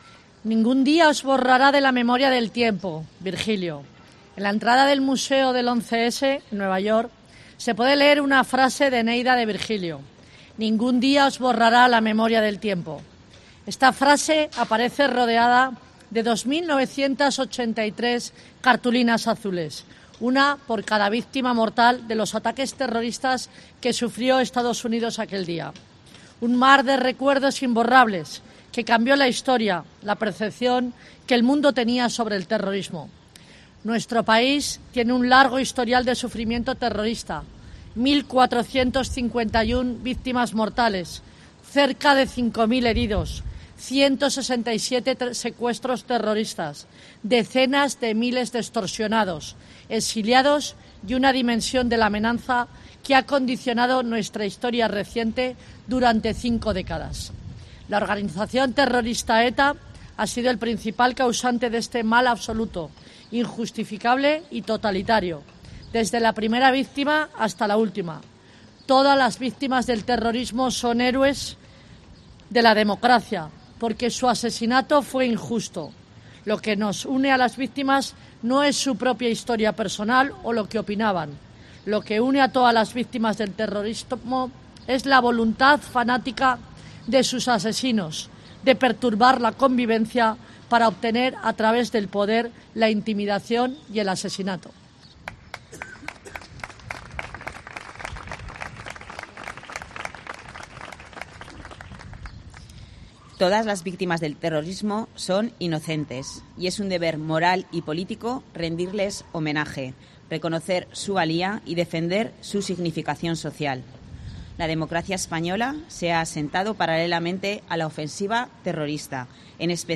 Lectura del manifiesto en el homenaje a Miguel Ángel Blanco
Zaragoza se ha sumado a los homenajes en recuerdo a Miguel Ángel Blanco, el concejal del PP de Ermua secuestrado y asesinado por la banda terrorista ETA el 13 de julio de 1997. En la plaza San Francisco se han depositado flores y se ha leído el manifiesto de la Fundación que lleva su nombre, al que han puesto voz la portavoz del PSOE en el Ayuntamiento de Zaragoza, Lola Ranera; la concejala delegada de víctimas del terrorismo, Ruth Bravo; y la alcaldesa de la ciudad, Natalia Chueca.